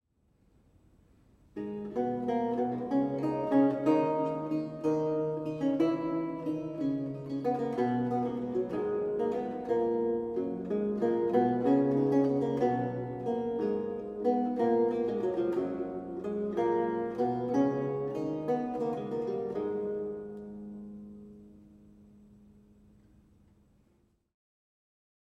Audio recording of a lute piece
a 16th century lute music piece originally notated in lute tablature